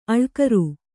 ♪ aḷkaru